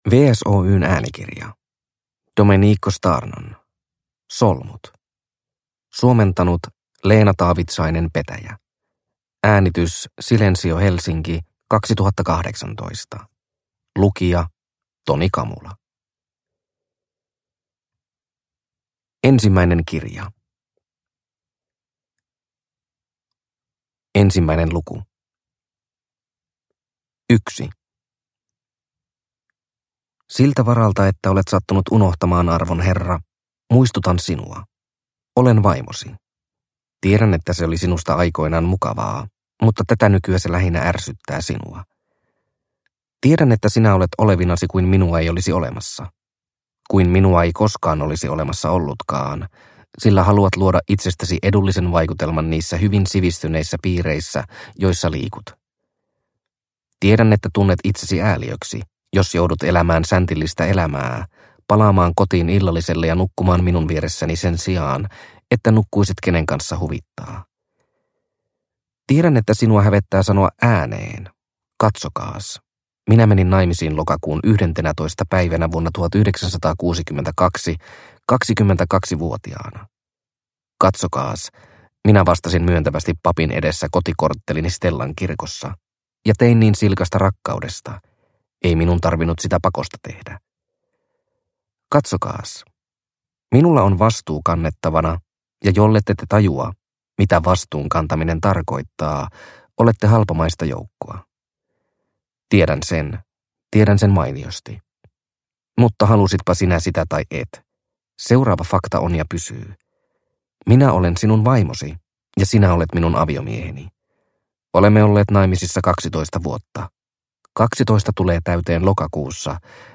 Solmut – Ljudbok – Laddas ner